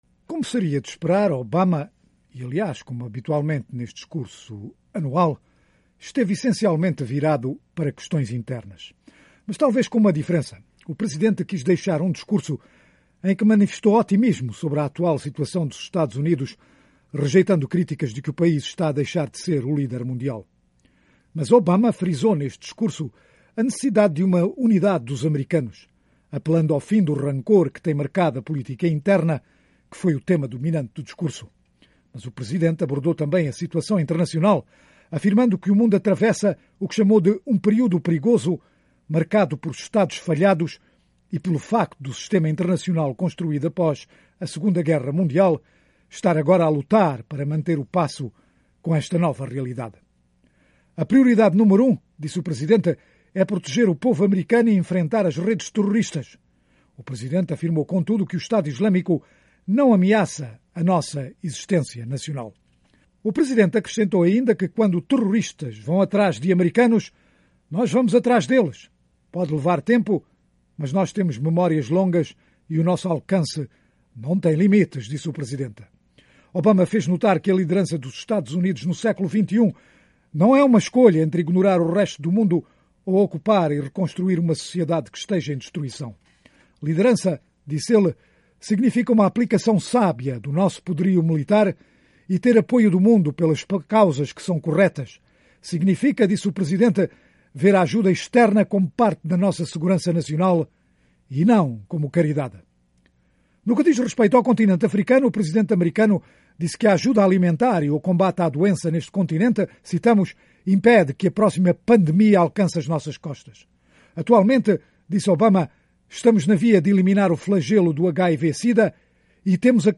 Discurso do estado da união - 4:06